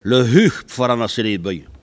Il chante pour faire avancer les boeufs
traction bovine
Saint-Jean-de-Monts